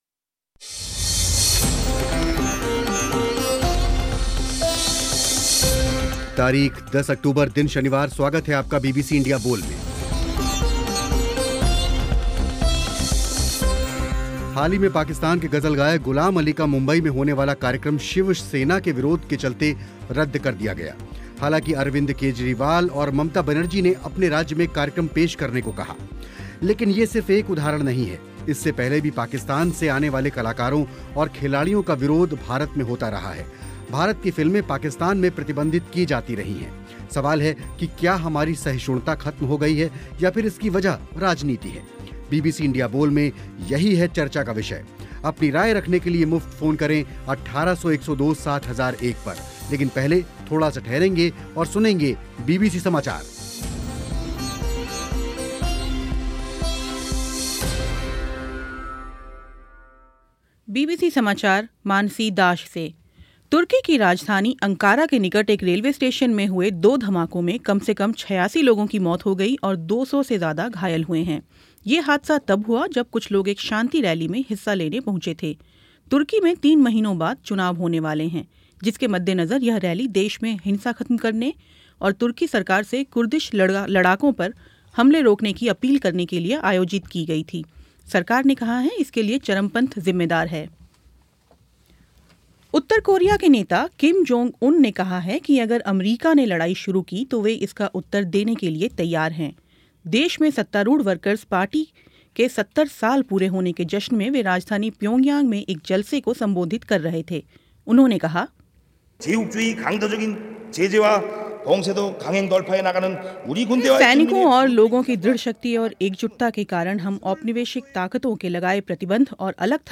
सवाल है कि क्या हमारी सहिष्णुता खत्म हो रही है या फिर इसकी वजह राजनीति है. बीबीसी इंडिया बोल में इसी बहस पर हुई चर्चा.